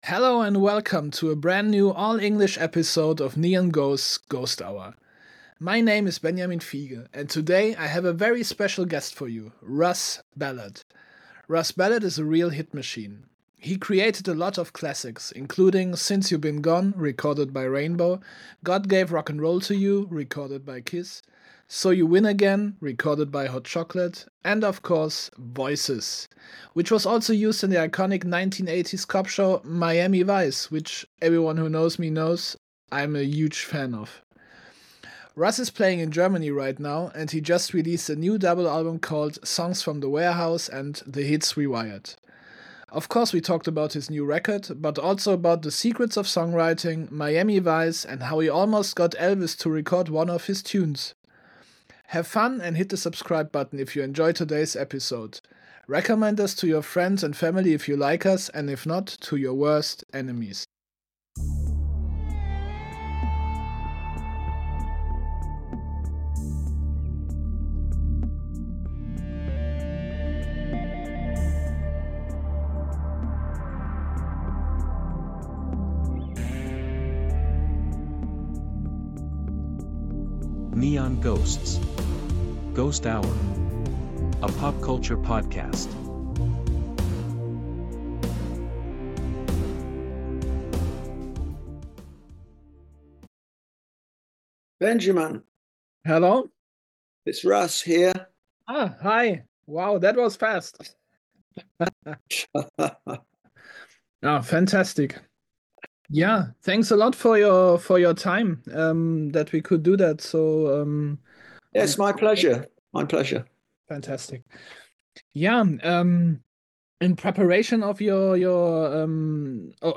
Im Interview: Russ Ballard - "Voices, I hear voices" ~ NEON GHOSTS: GHOST HOUR Podcast